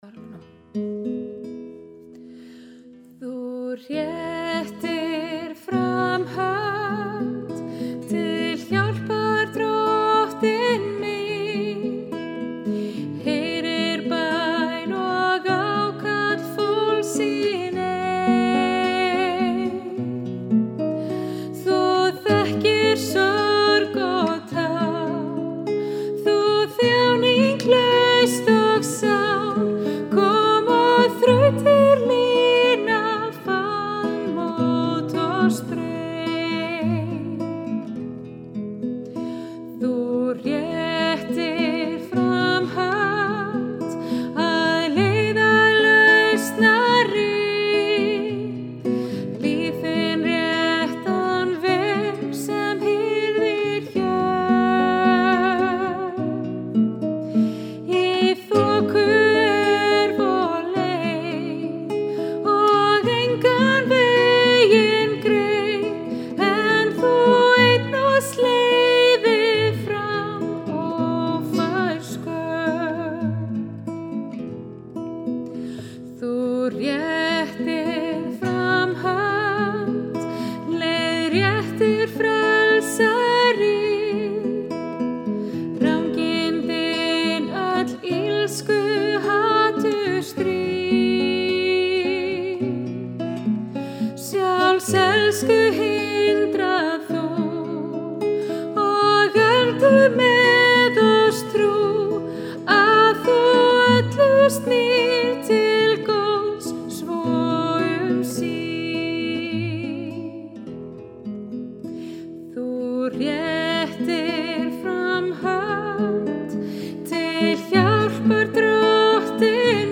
Ég þýddi fyrir nokkrum árum bænasálm sem ég held mikið upp á vegna þess að hann skilgreinir svo vel bænina.